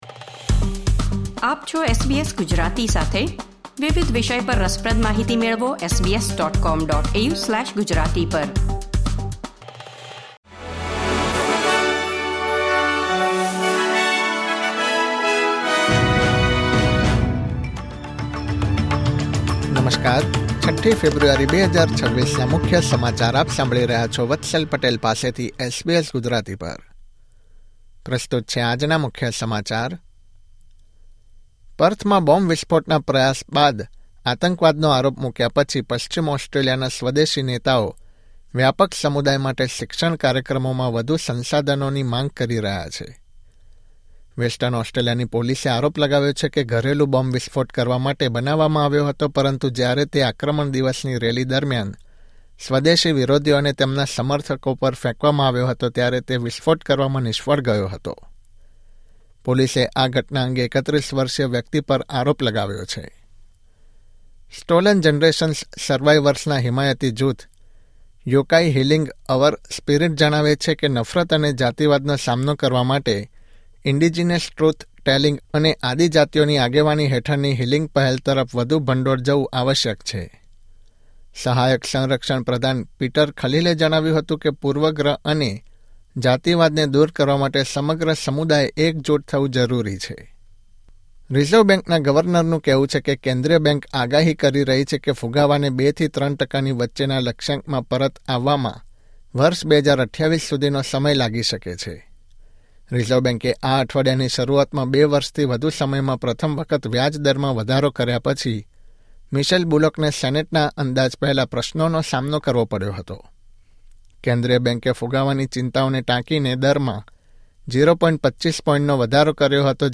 Listen to the latest Australian news from SBS Gujarati